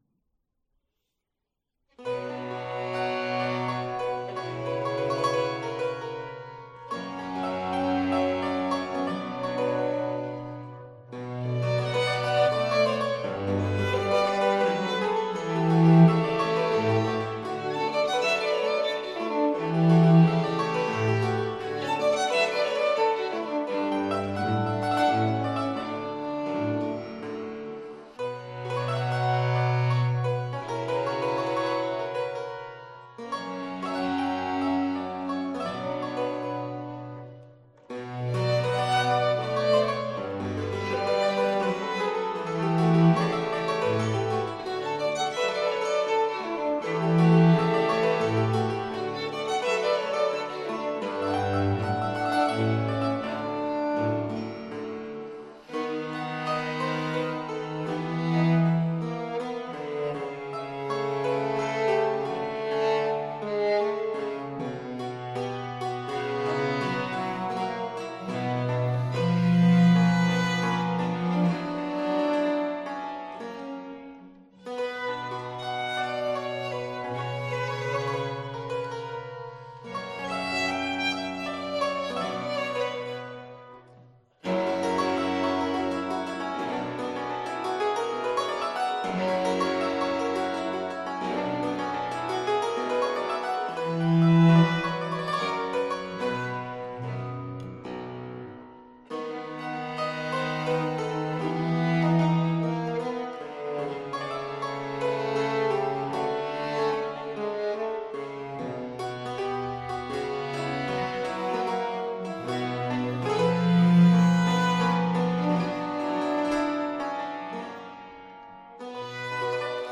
PerformerThe Raritan Players
Subject (lcsh) Trio sonatas